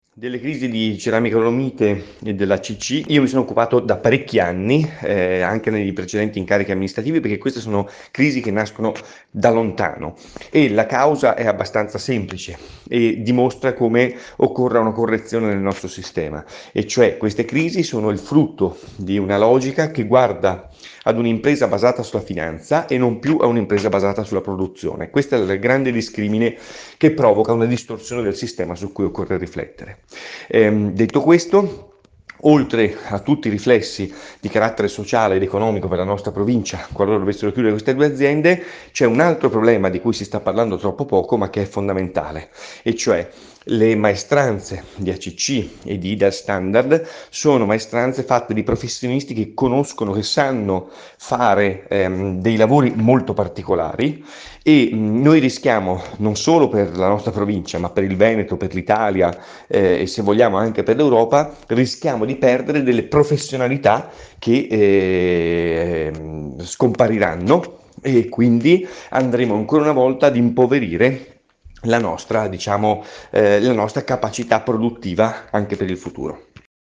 LA MANIFESTAZIONE PER LA DIFESA DEL TESSUTO PRODUTTIVO DELLA PROVINCIA A BORGO VALBELLUNA – Radio Più